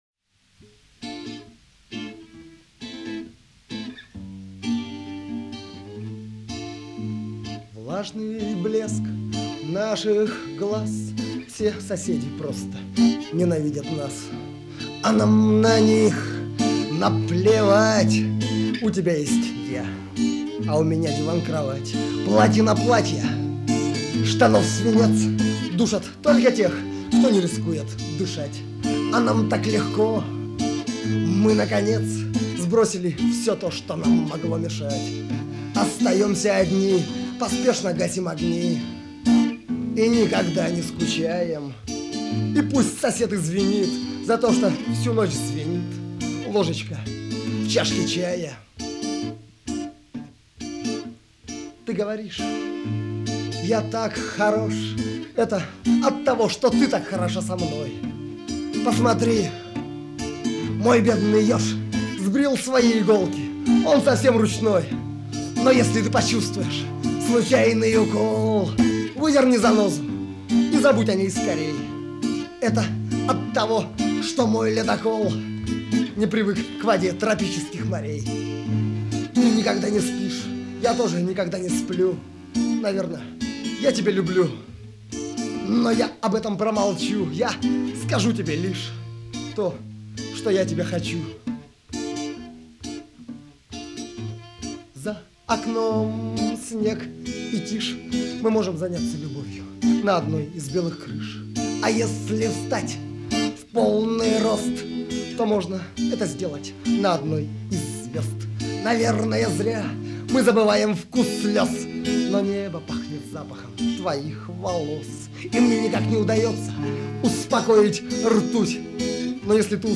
Ноябрь 1984, Москва